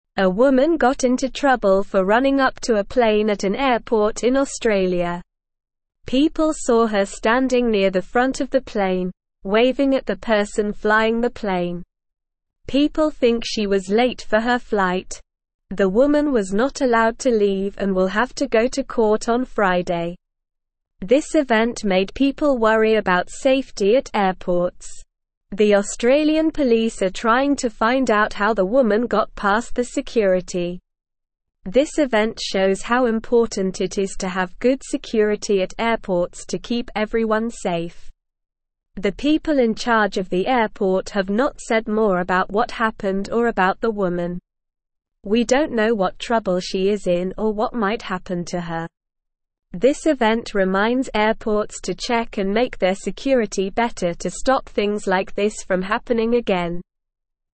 Slow
English-Newsroom-Lower-Intermediate-SLOW-Reading-Trouble-at-Airport-Lady-Runs-to-Plane.mp3